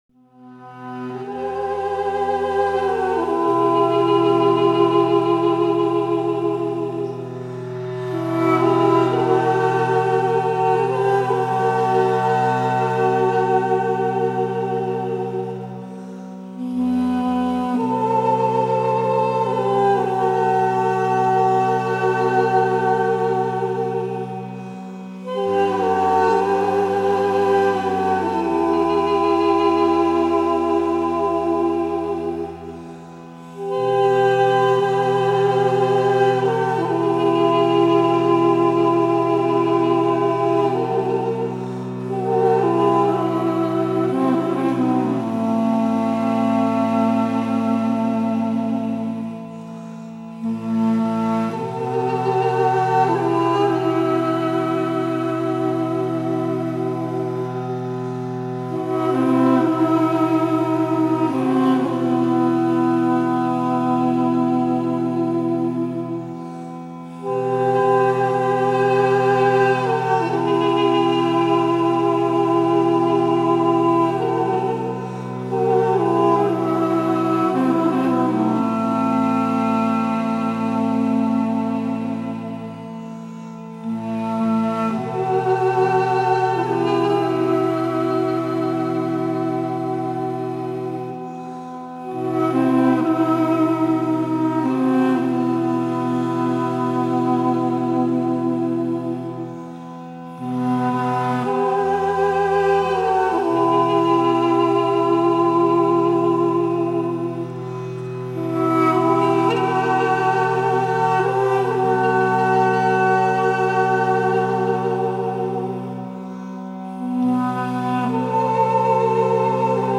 Армянский дудук...как классно......